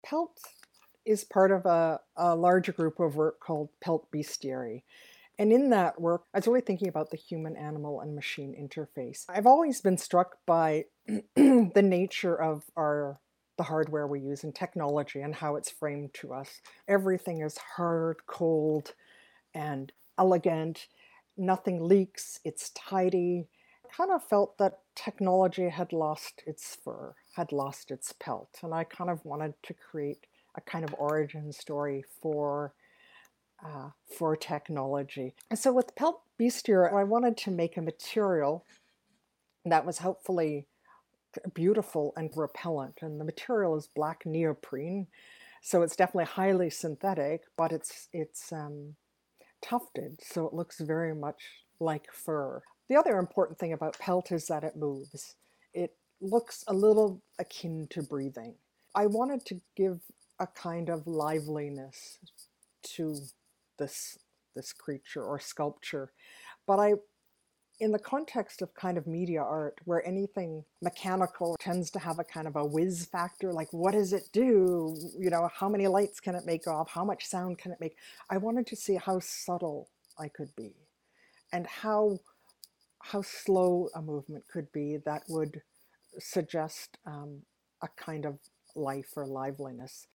Material/technique: neoprene rubber; steel; nylon substrate; custom electronics; two motors; felt
Originally one of six animal-like kinetic sculptures, this work seeks to humanize technology through rhythmic inhalations and exhalations.